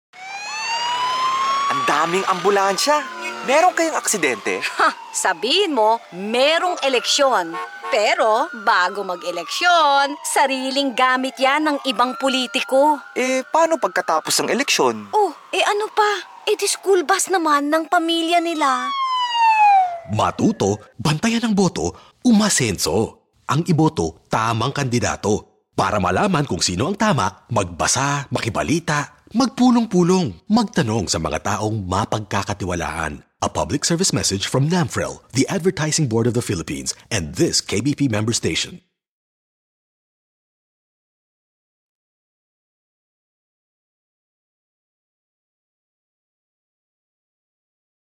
Radio Ads
Radio Ad_ Ambulansya.mp3